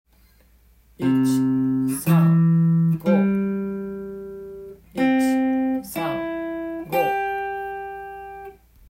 度数練習
①のように度数くちずさみながら練習し